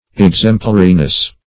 Search Result for " exemplariness" : The Collaborative International Dictionary of English v.0.48: Exemplariness \Ex"em*pla*ri*ness\, n. The state or quality of being exemplary; fitness to be an example.